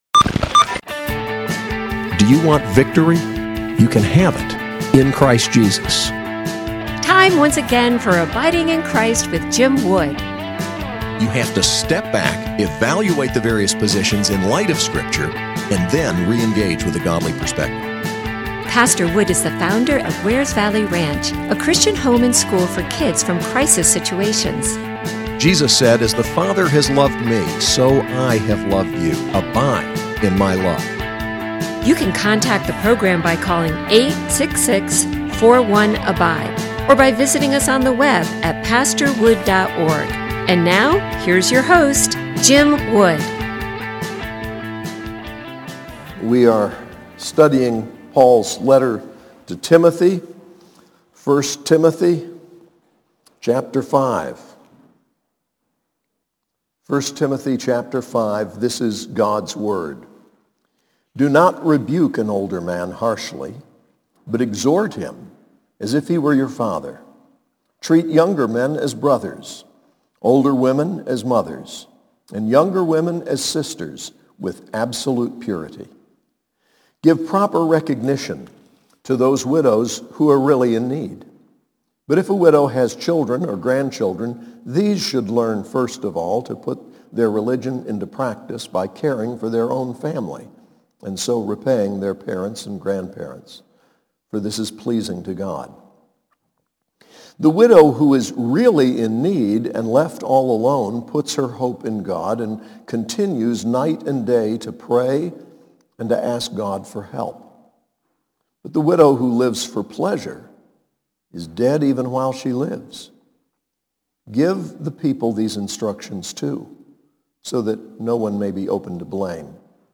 SAS Chapel: 1 Timothy 5:1-16